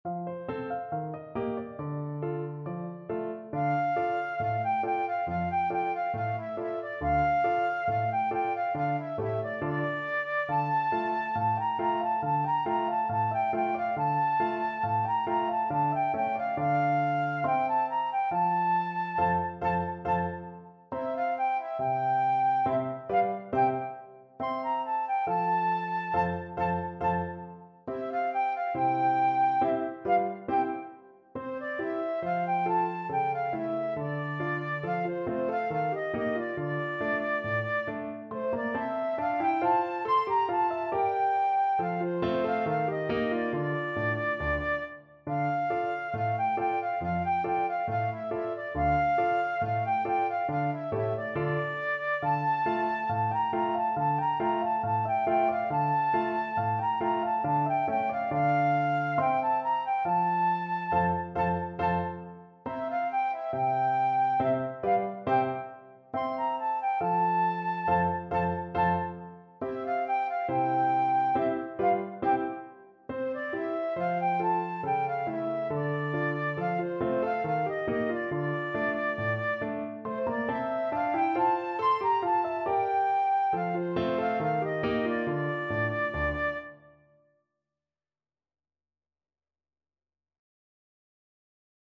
Flute
Traditional Music of unknown author.
2/4 (View more 2/4 Music)
D minor (Sounding Pitch) (View more D minor Music for Flute )
Slow =69